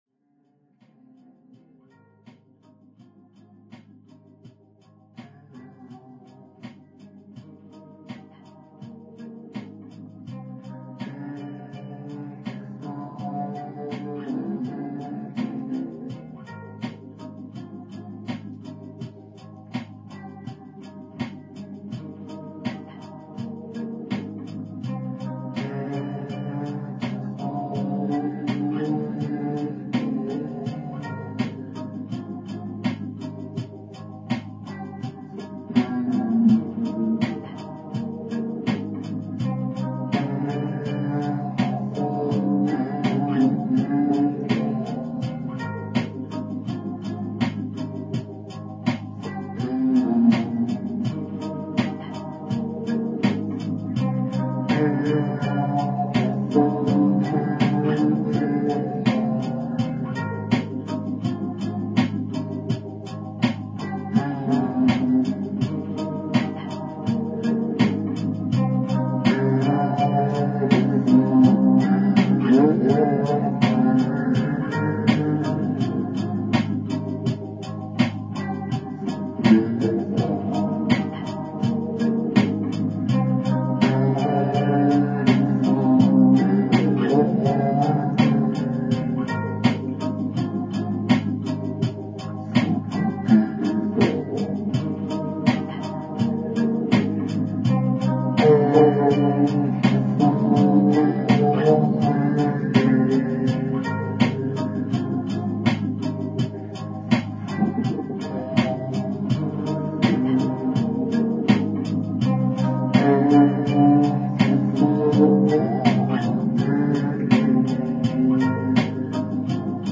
♭E/Cm 80bpm